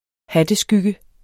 Udtale [ ˈhadə- ]